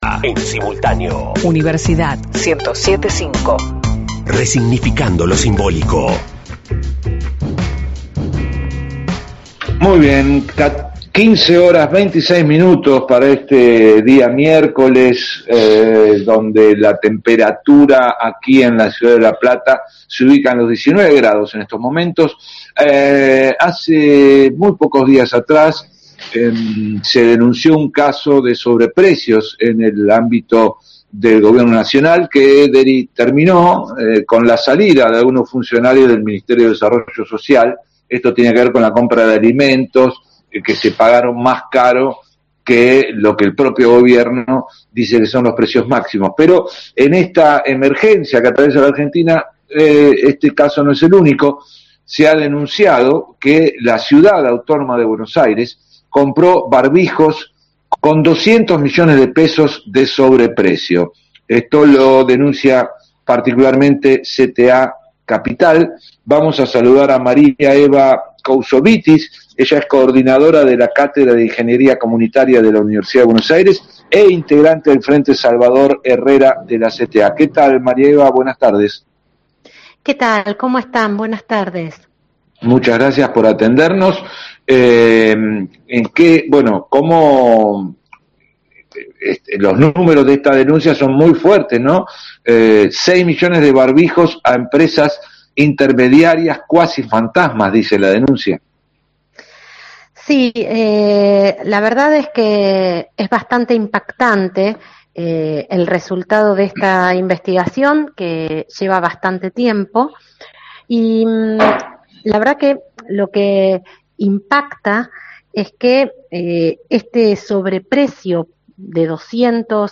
dialogó esta tarde con radio Universidad, acerca de la denuncia por la compra con 200 millones de pesos de sobreprecio de barbijos, por parte del Gobierno de la Ciudad Autónoma de Buenos Aires.